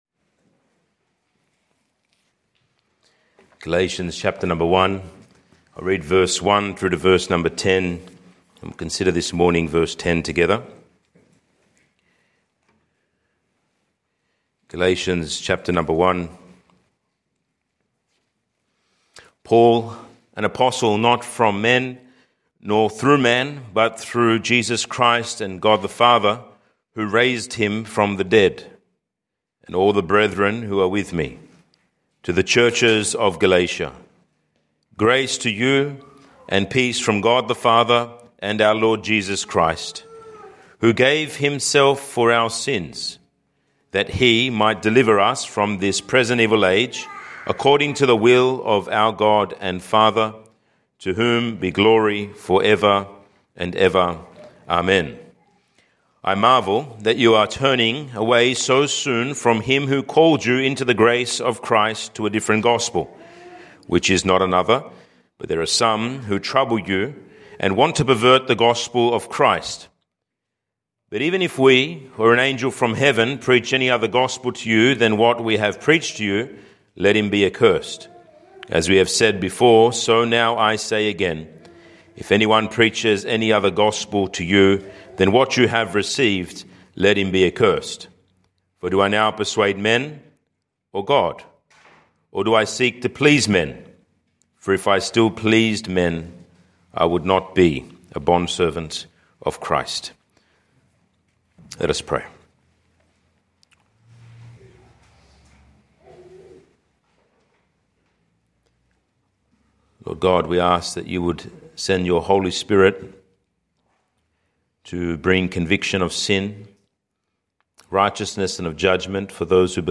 Camden Valley Baptist Church congregation, evangelical baptist
sermon.mp3